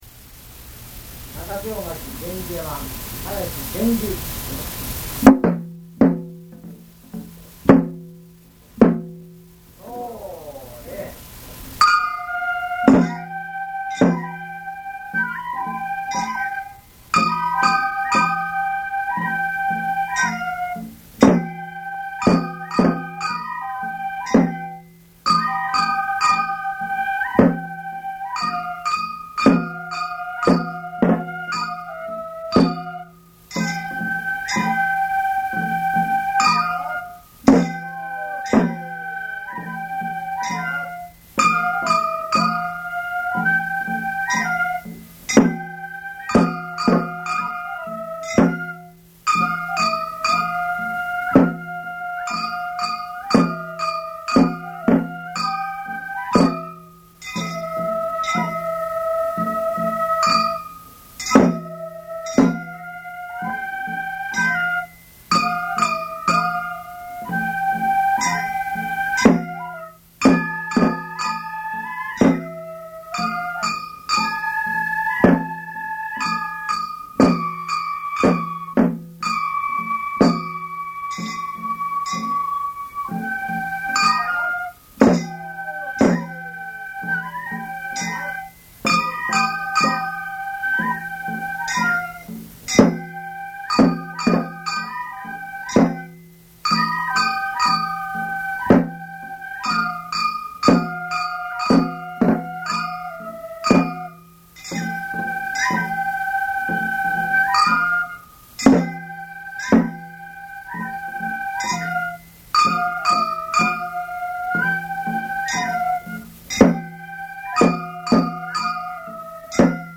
源氏 源氏山の囃子の中でも基本的曲目。
囃子方
太鼓
笛
鉦
昭和62年11月1日　京都太秦　井進録音スタジオ